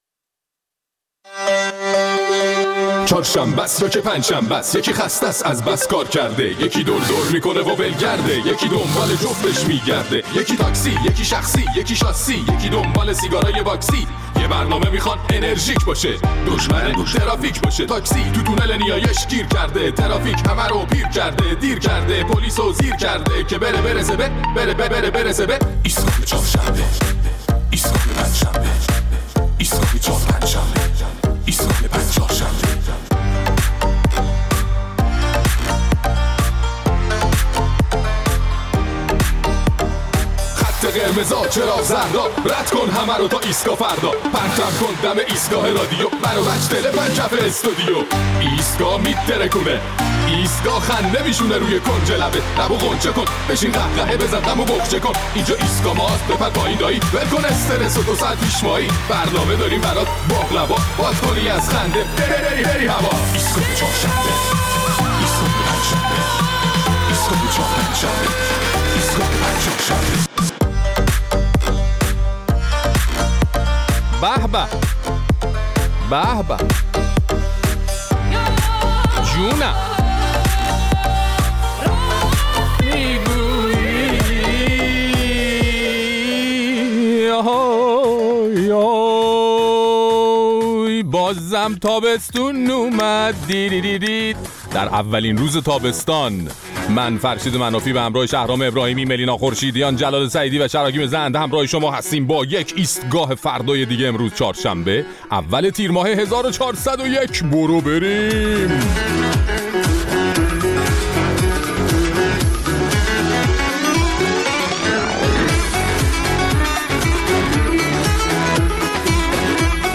در این برنامه نظرات شنوندگان ایستگاه فردا را در مورد صحبت‌های یکی از اعضای خبرگان که خواستار تدریس شیوه حکمرانی رهبر نظام شده بود می‌شنویم.